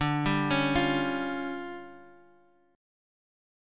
DmM7bb5 chord